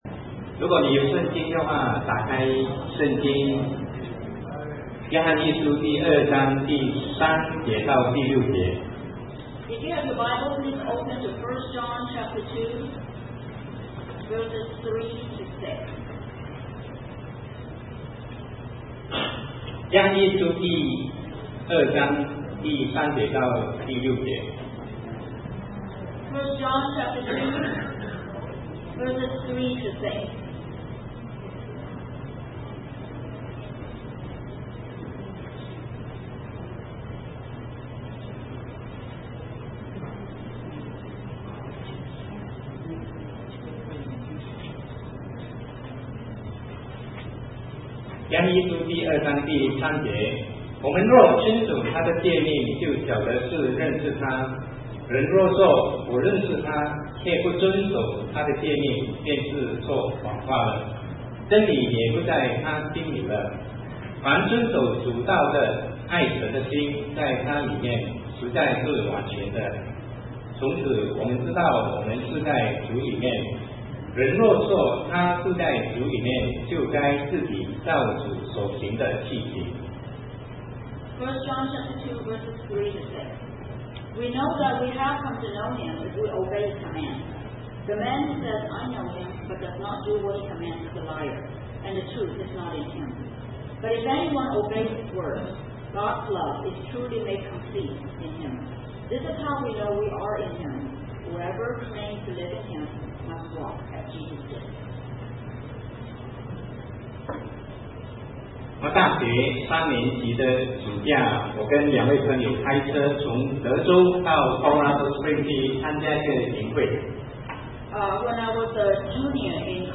Sermon 2009-08-23 What Does it Mean to Know God?